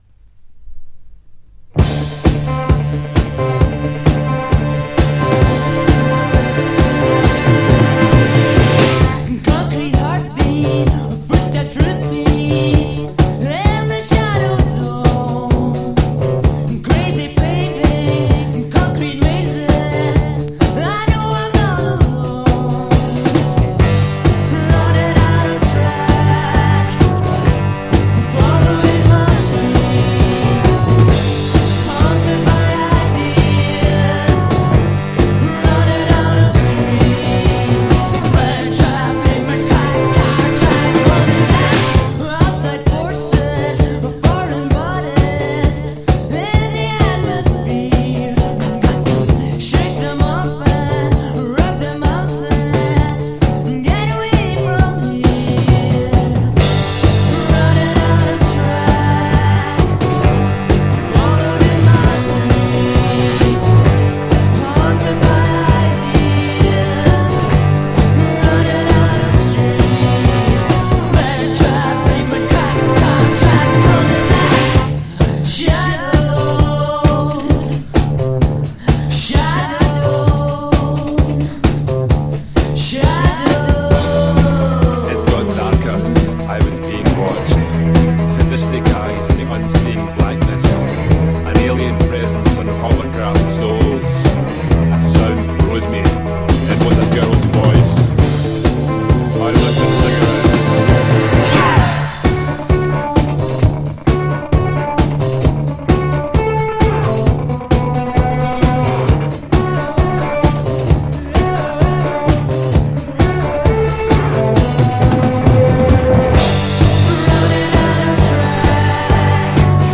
This is Scottish new wave apparently.